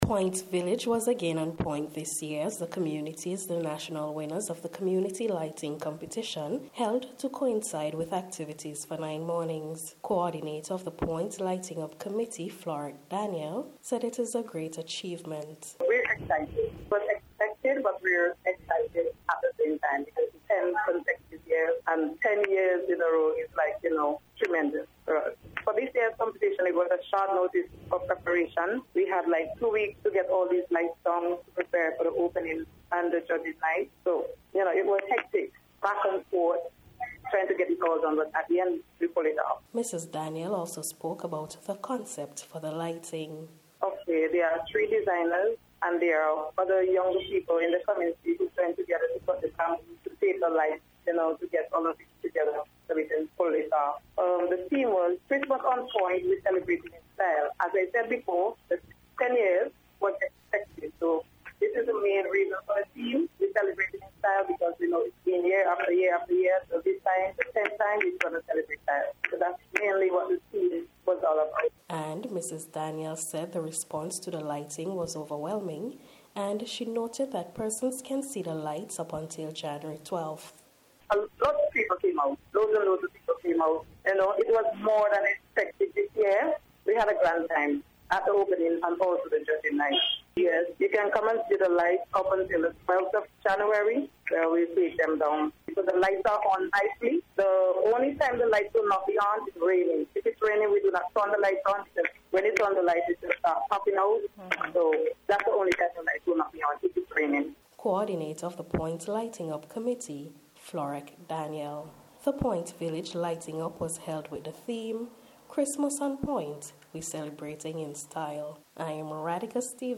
NBC’s Special Report – Thursday December 29th 2022